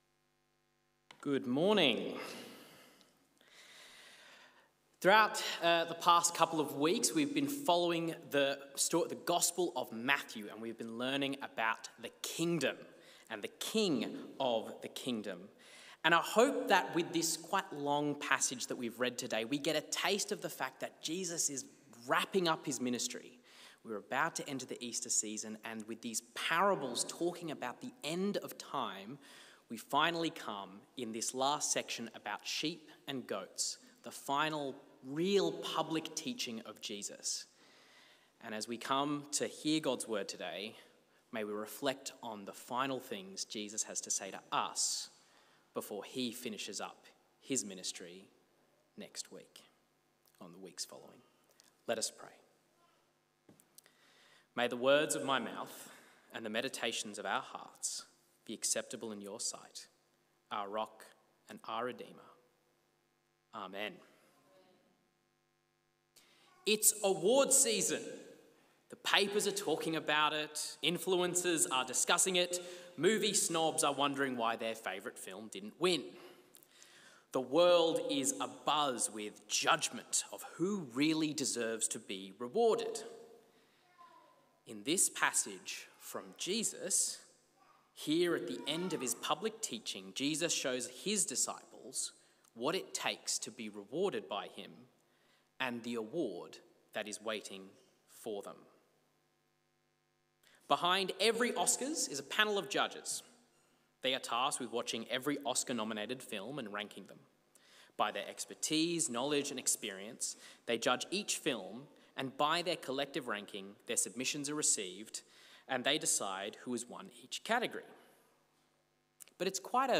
A sermon on Matthew 25